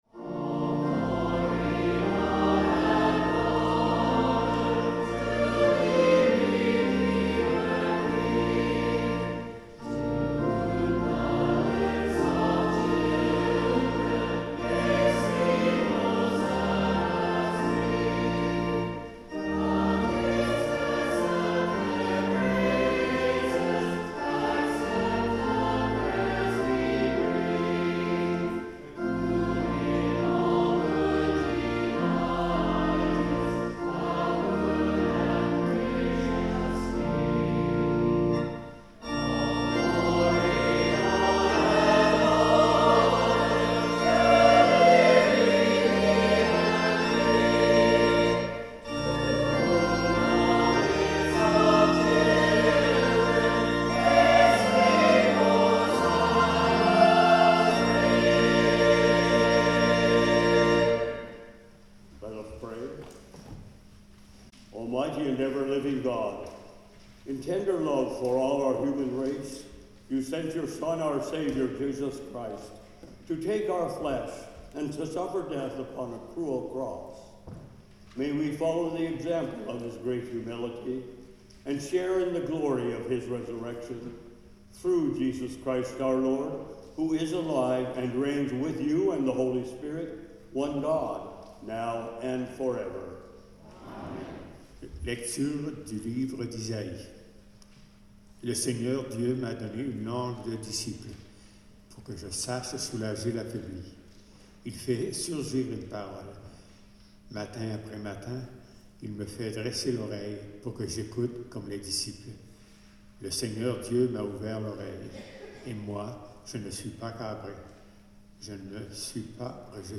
Sermons | St John the Evangelist
This was beyond the reach of microphones and is not included in the recording.
The Lord’s Prayer (sung)